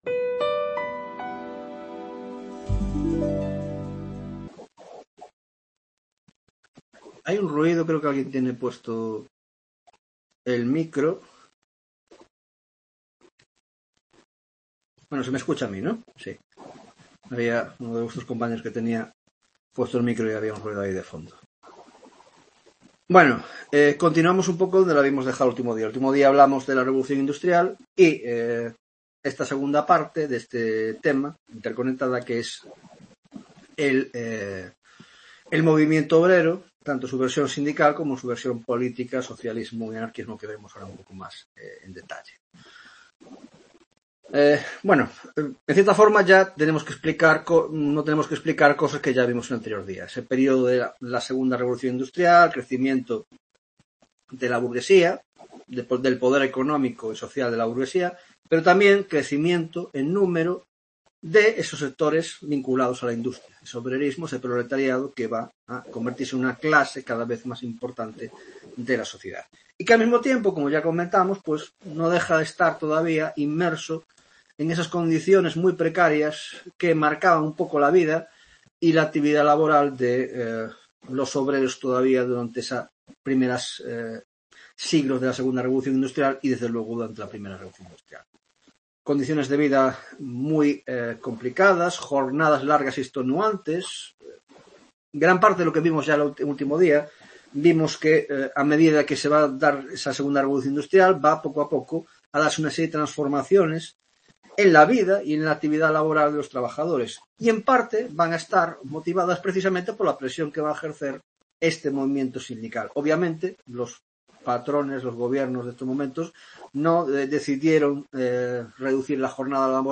11ª tutoria de Historia Contemporánea - Movimeinto Obrero: Sindicalismo, Socialismo y Anarquismo.